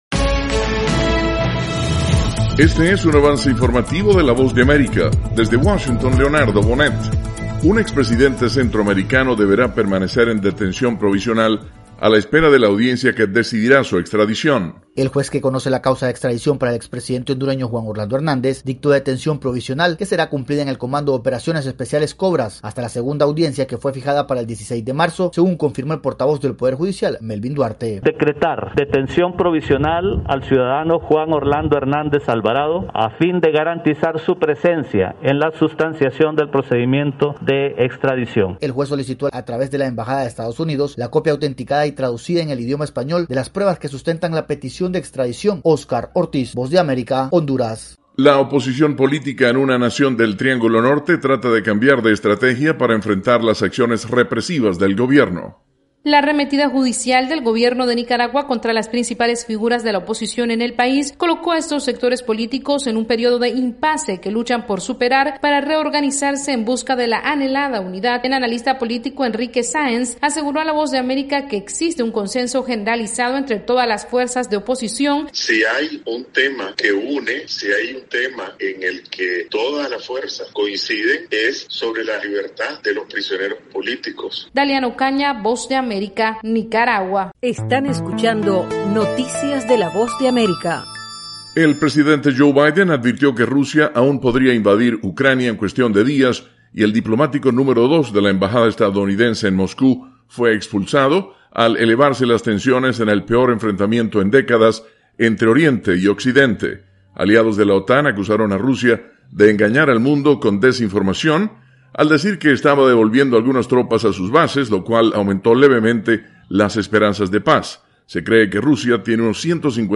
Avance Informativo - 3:00 PM